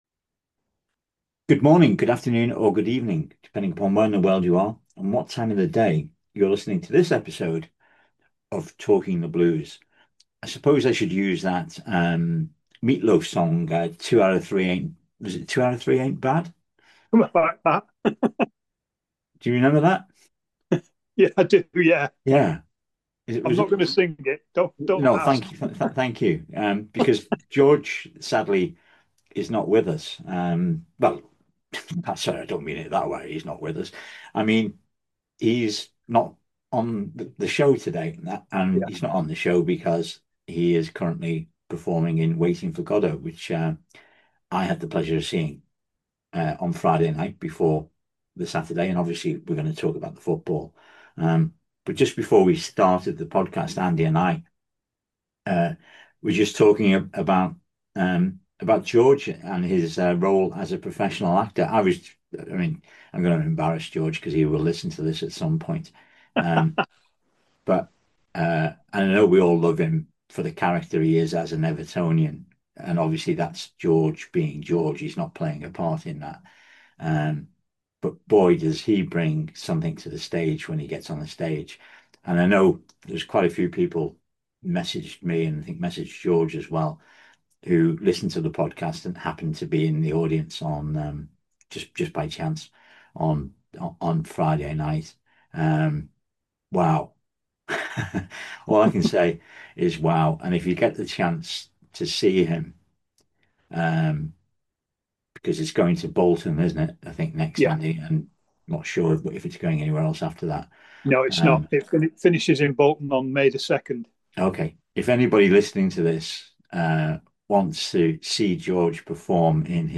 Three passionate Everton supporters discuss the Blues. Weekly podcast discussing both on and off field matters. Usually with a bit of humour thrown in!